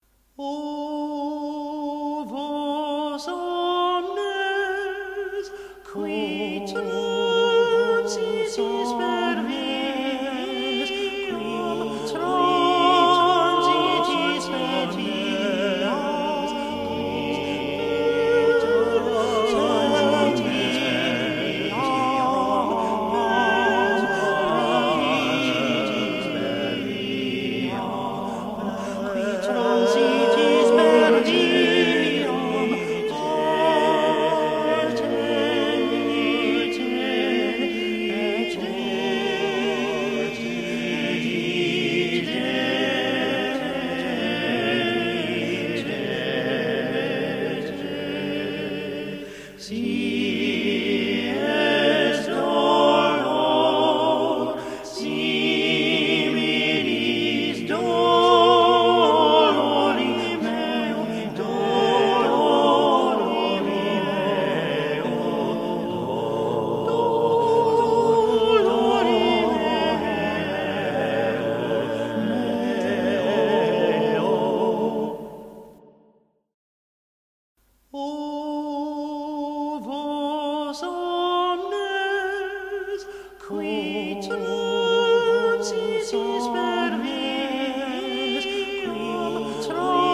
3Jachet_O_vos_omnes_sung_by_the_dwsChorale.mp3